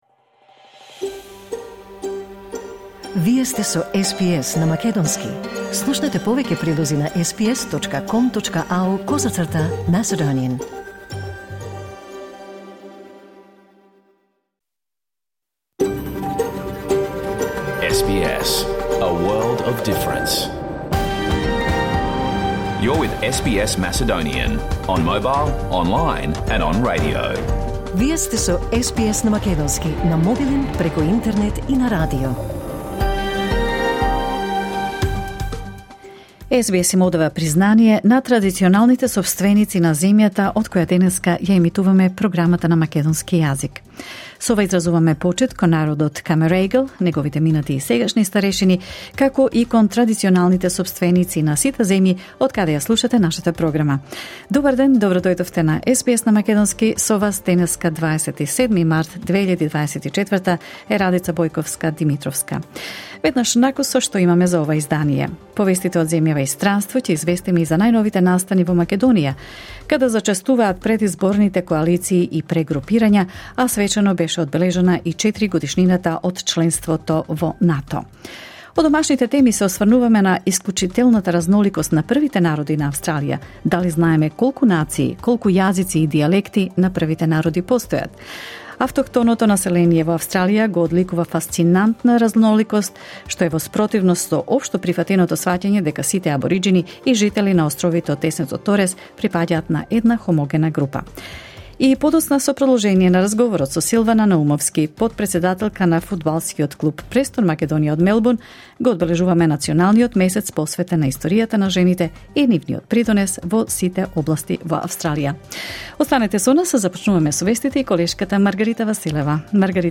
SBS Macedonian Program Live on Air 27 March 2024